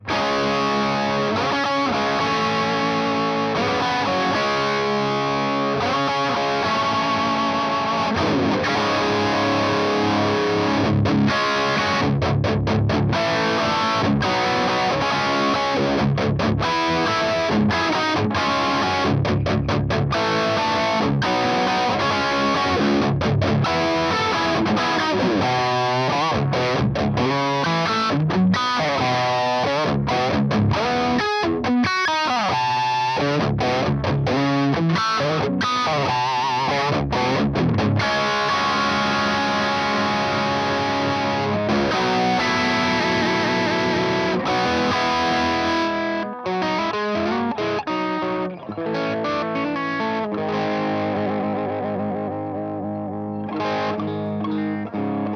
(why do I always play that riff when I test things) I just think it's a nice rock tone, and I really like how the amp cleans up at the end of the clip, and gets sort of chimey with this speaker. Attachments Budda with Weber H30.mp3 Budda with Weber H30.mp3 866.1 KB · Views: 251